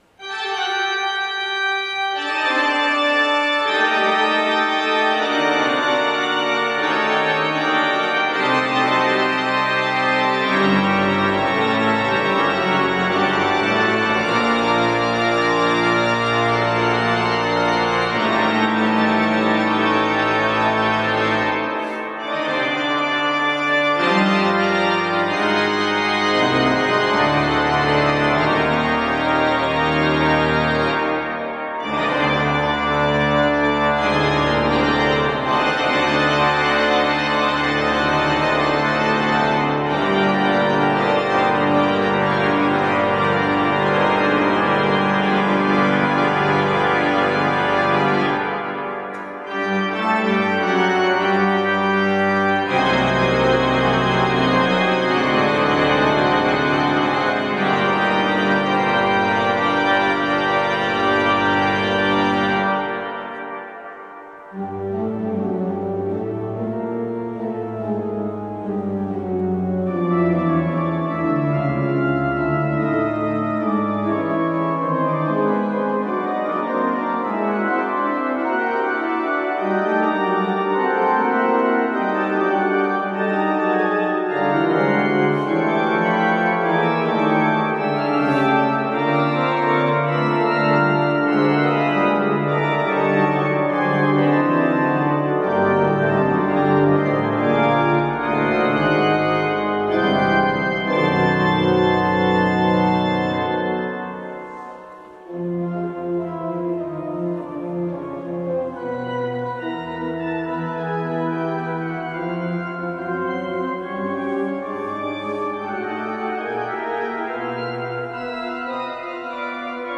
Koncert organowy „Krzyżu Chrystusa” 9 marca 2013
Marian Sawa – 2 Preludia organowe na temat pieśni: